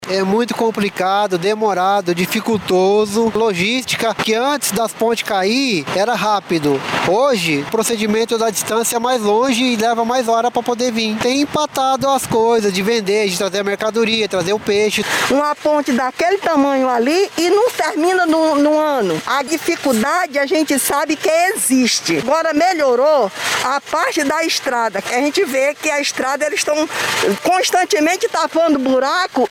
Enquanto as obras não finalizam, a população relata as dificuldades para trafegar pela via.